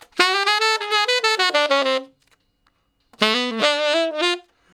066 Ten Sax Straight (D) 14.wav